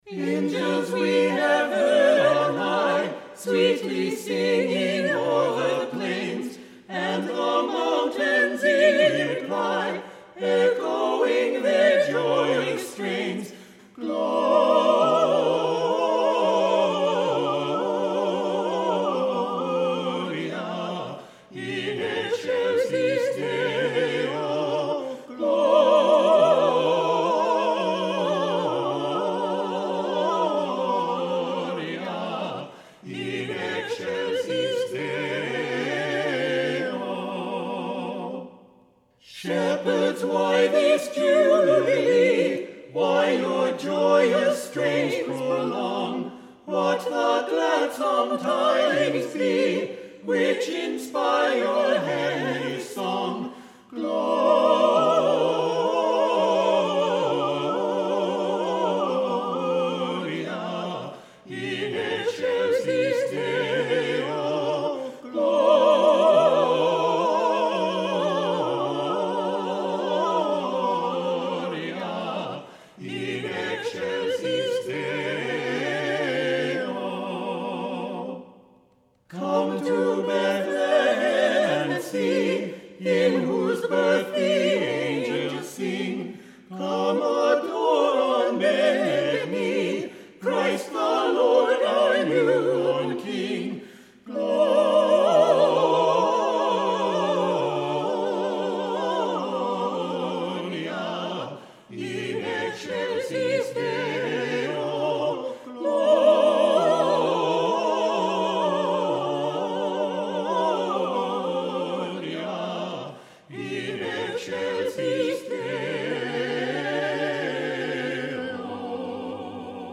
This San Jose Carolers group is the premier Christmas Caroling group in the South Bay and Silicon Valley. With their beautiful voices and elegant, festive Victorian attire, they bring the true spirit of the season to any event.